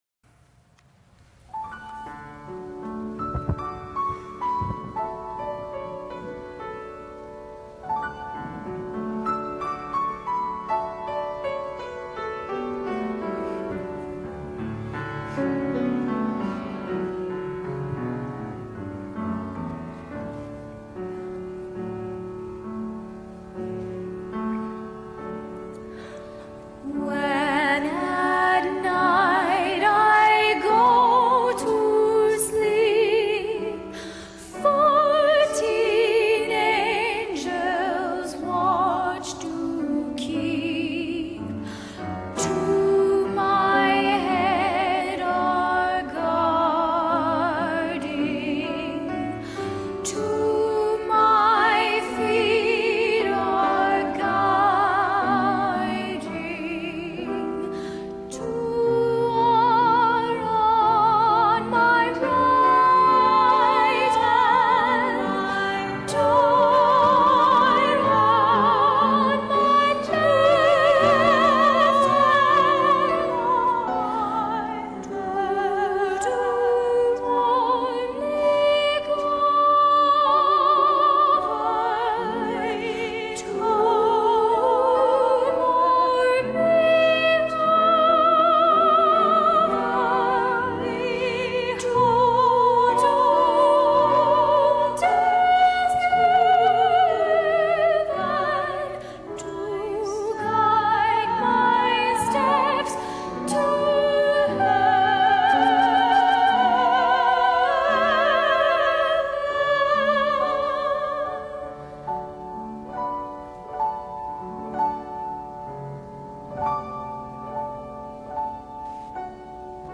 choir.mp3